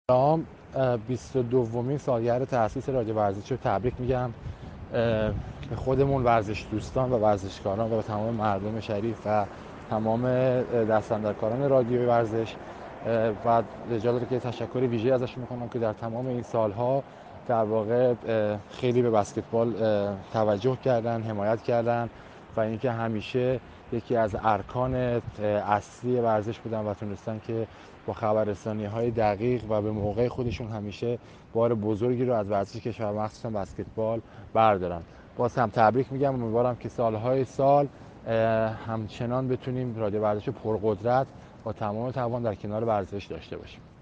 صمد نیكخواه بهرامی كاپیتان تیم ملی بسكتبال كشور تولد 23 سالگی رادیو ورزش را تبریك گفت.